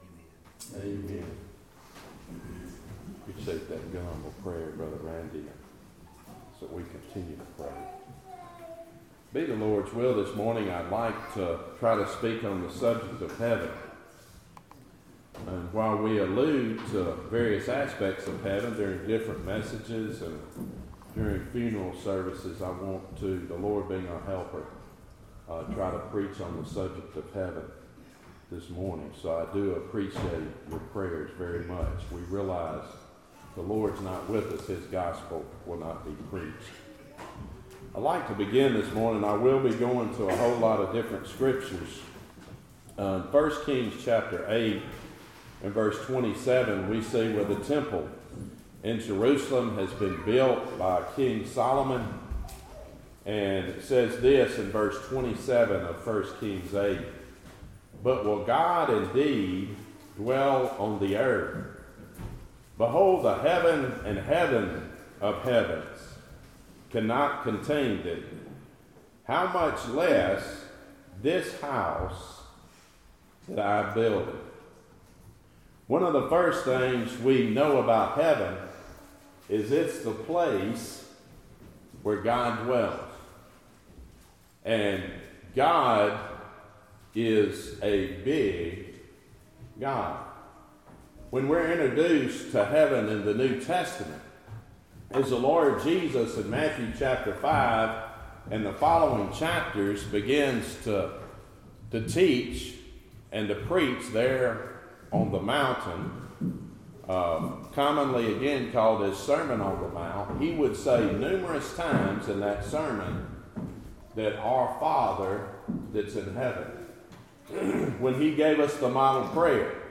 Topic: Sermons